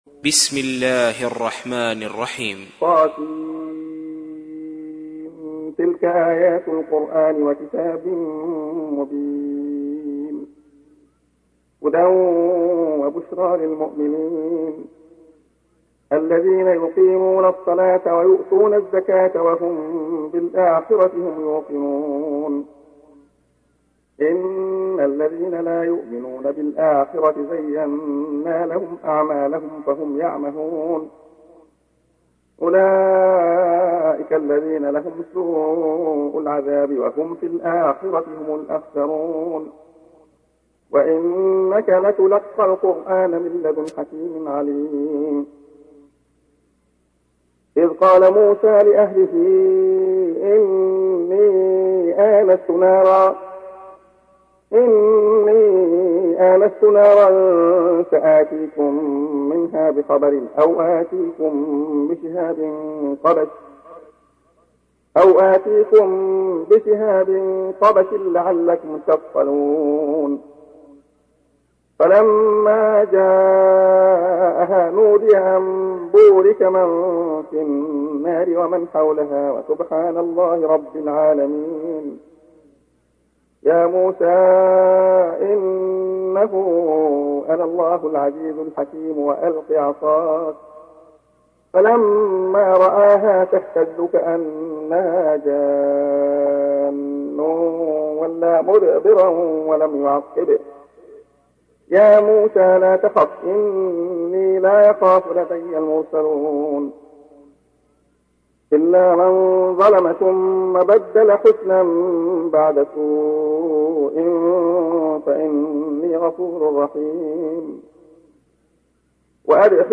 تحميل : 27. سورة النمل / القارئ عبد الله خياط / القرآن الكريم / موقع يا حسين